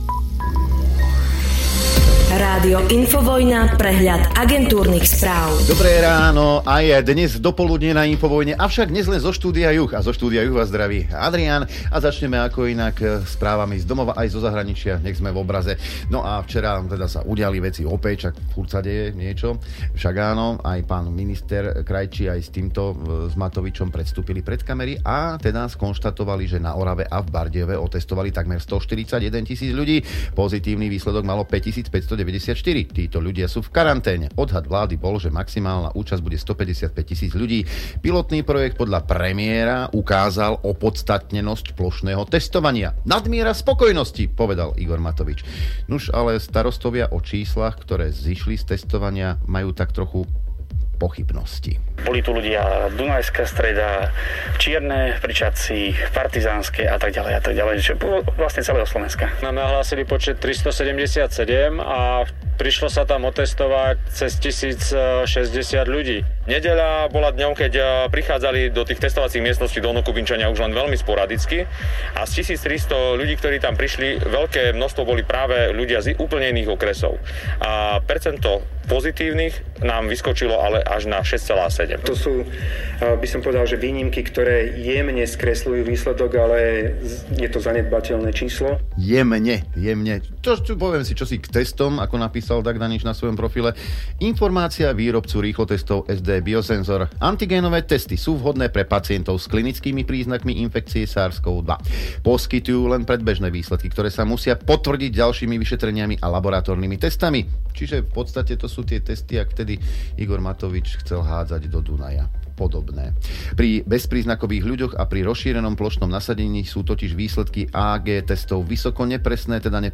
Z technických príčin je relácia neúplná.